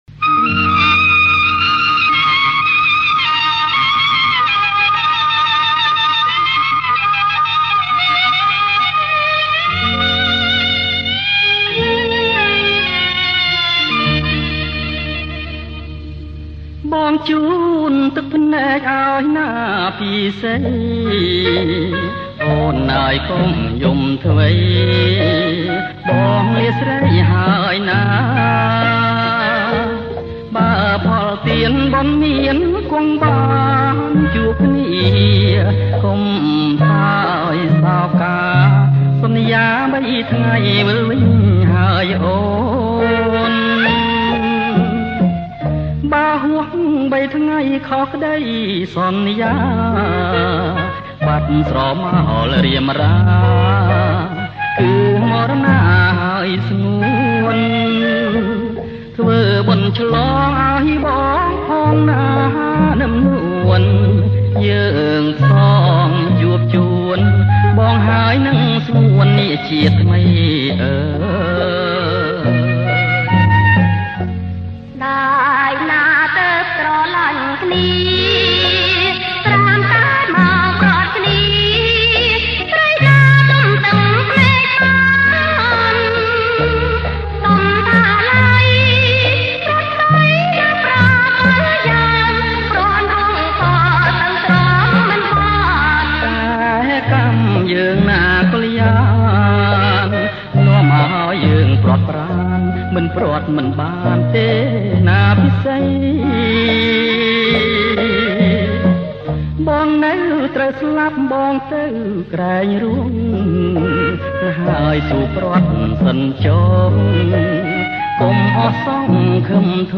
• ប្រគំជាចង្វាក់  Bolero-Slow